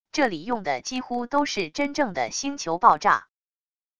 这里用的几乎都是真正的星球爆炸wav音频